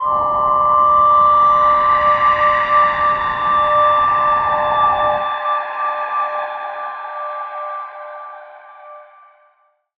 G_Crystal-D6-f.wav